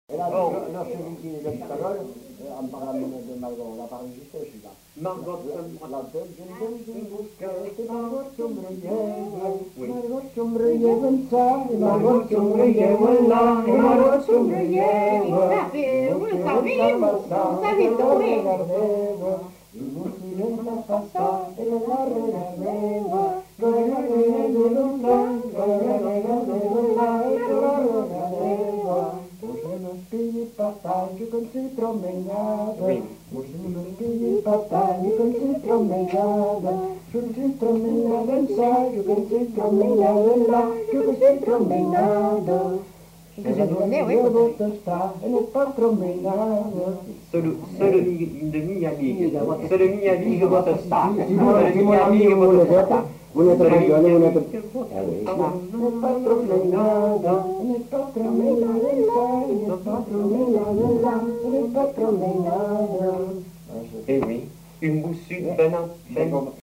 Aire culturelle : Grandes-Landes
Genre : chant
Type de voix : voix mixtes
Production du son : chanté
Danse : rondeau